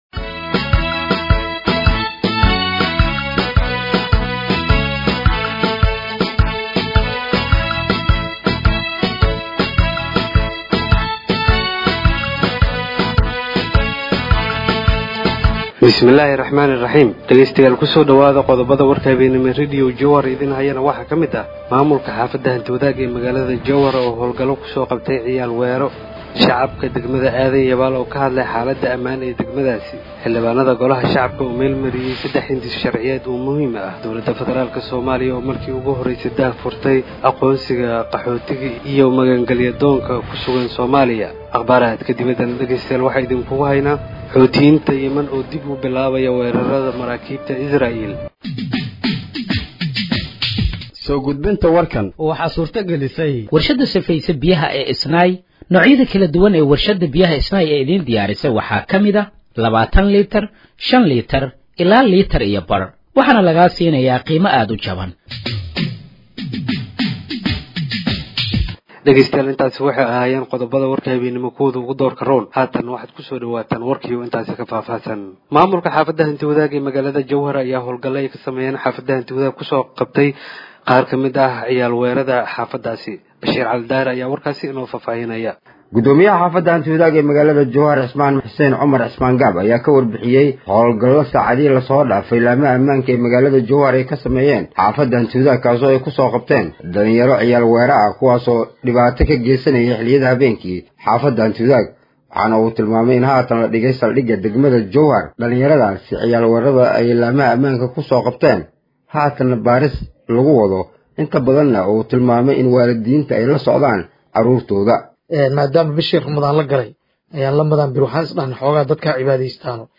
Dhageeyso Warka Habeenimo ee Radiojowhar 12/03/2025
Halkaan Hoose ka Dhageeyso Warka Habeenimo ee Radiojowhar